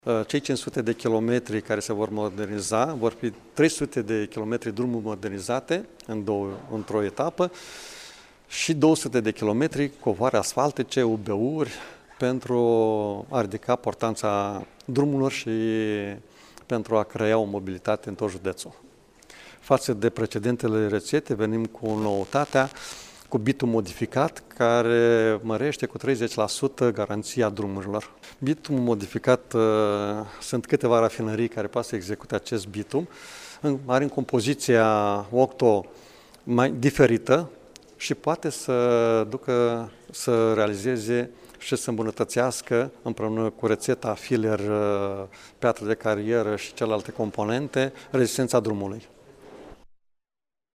Președintele Consiliului Județean Iași, Maricel Popa